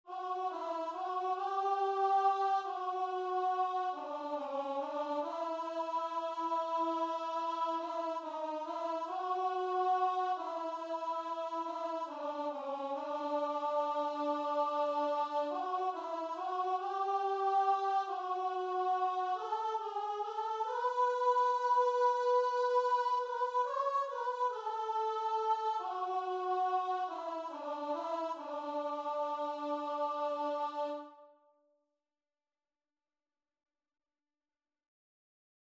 9/4 (View more 9/4 Music)
Guitar and Vocal  (View more Easy Guitar and Vocal Music)